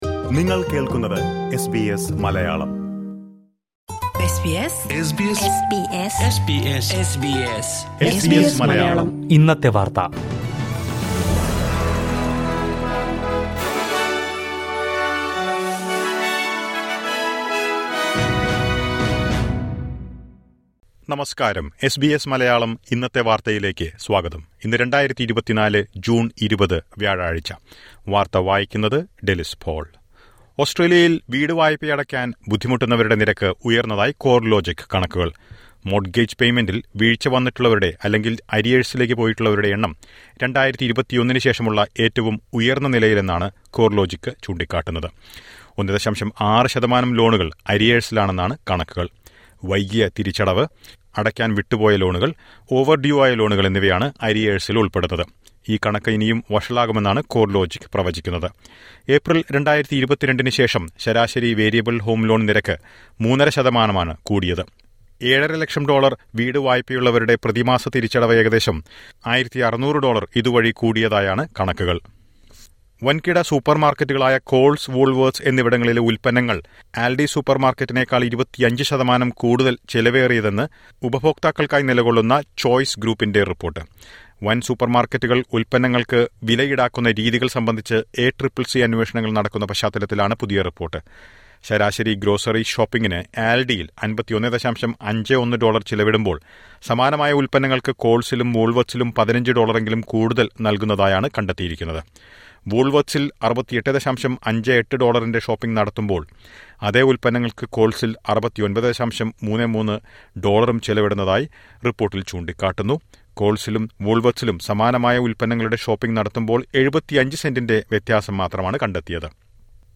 2024 ജൂൺ 20ലെ ഓസ്‌ട്രേലിയയിലെ ഏറ്റവും പ്രധാന വാര്‍ത്തകള്‍ കേള്‍ക്കാം...